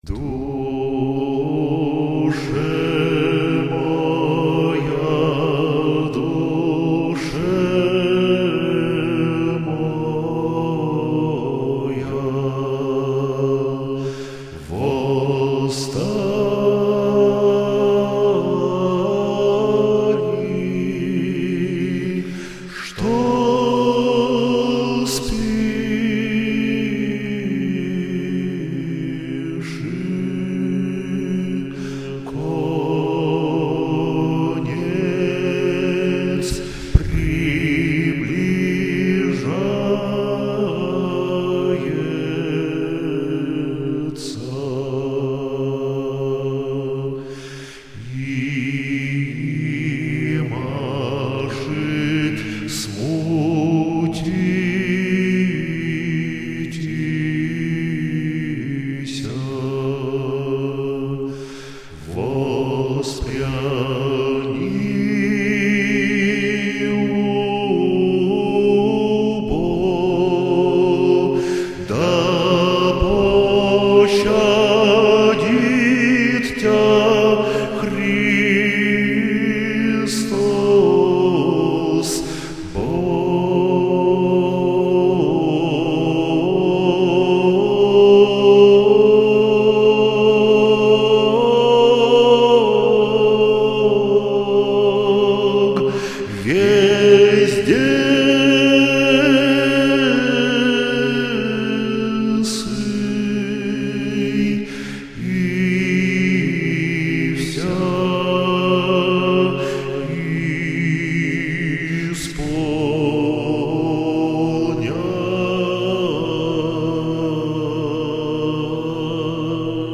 тема:   религия
(Музыкальное сопровождение : Хор Свято-Николо Тихона Лухского мужского монастыря (село Тимирязево Лухского р-на Ивановской области)